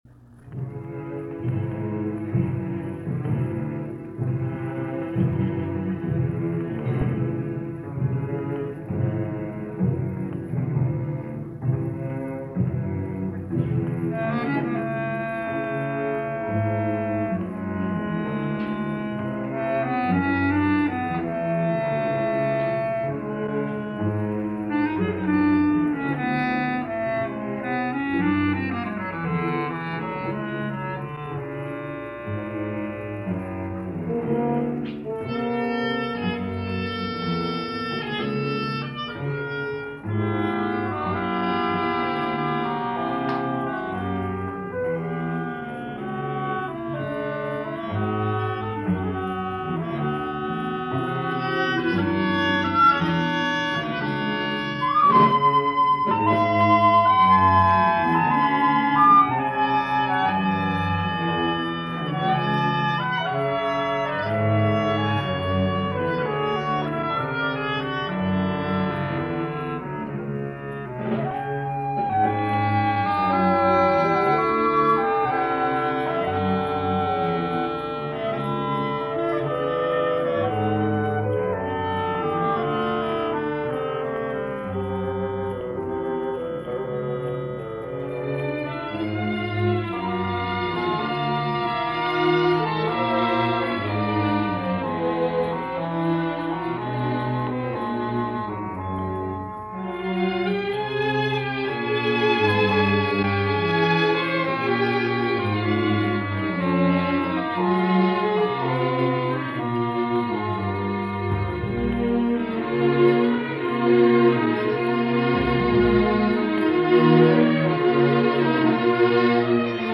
not earth-shattering, nor was it saccharine sweet